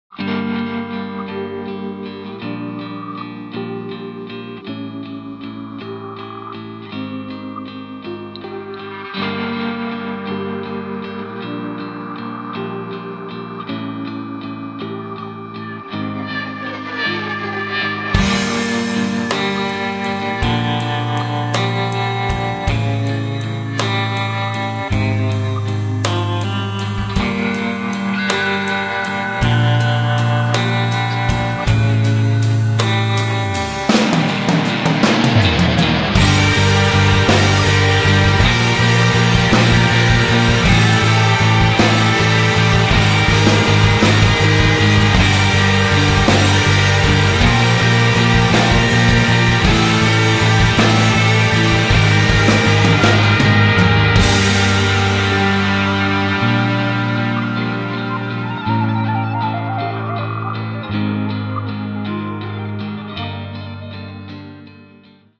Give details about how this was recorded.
Recorded and mixed at Village Recorders